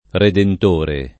redentore [
redent1re] s. m.; f. ‑trice — in uso assoluto, con R- maiusc., per antonomasia Gesù Cristo: Egli è desso, il Redentor [el’l’ H dd%SSo, il redent1r] (Manzoni) — precisato da una specificaz., con r- minusc., «colui che riscatta» qualcuno o qualcosa: acciò che la Italia, dopo tanto tempo, vegga uno suo redentore [